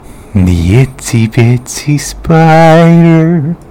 infinitefusion-e18/Audio/SE/Cries/GALVANTULA.mp3 at 681ab5ab3f802a9c966c5dafb1ce5480aa3b16d2